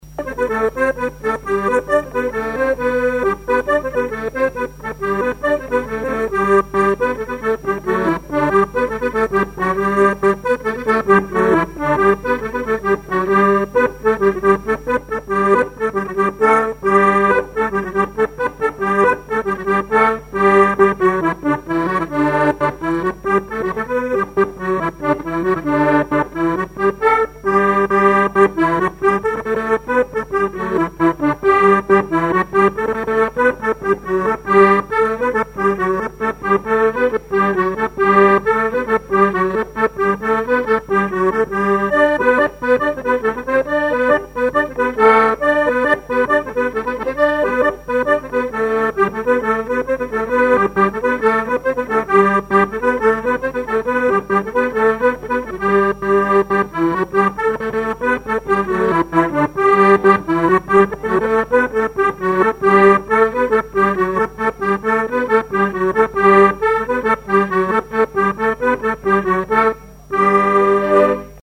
danse : branle : courante, maraîchine
collectif de musiciens pour une animation à Sigournais